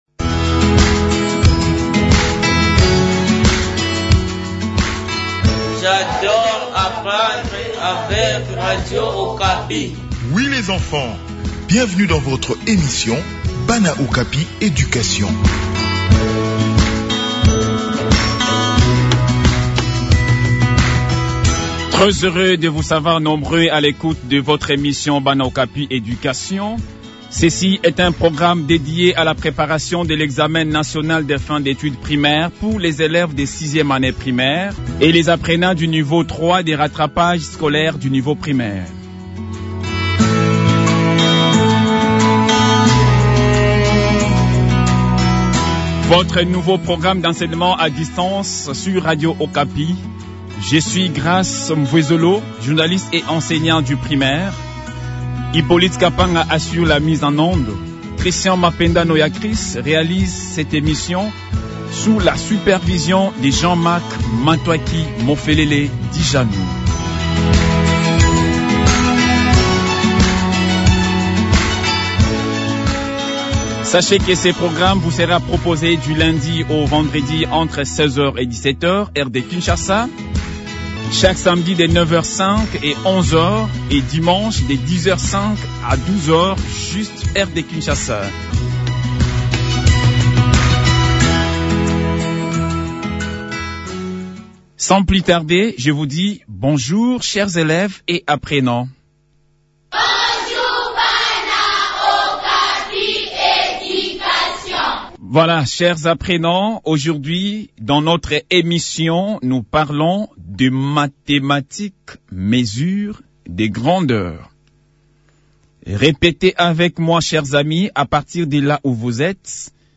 Chaque jour, une leçon pertinente rentrant dans le cadre des épreuves nationales de fin d'études primaires, est systématiquement développée sur les ondes de la Radio de la Paix entre 16h-17h. Aujourd'hui, il est question des mathématiques, spécifiquement des mesures des grandeurs.